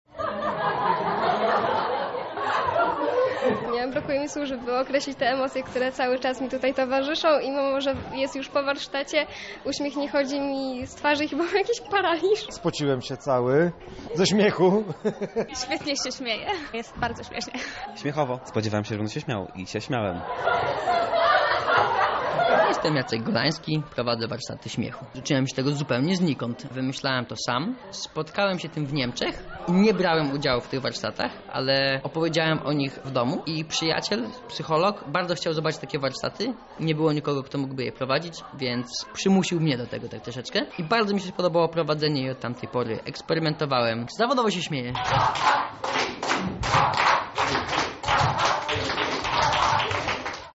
Udawany śmiech jest tak samo zdrowy, jak ten wywołany przez dowcip czy zabawną sytuację. Salwy śmiechu wypełniły wczoraj kawiarnię Kawka.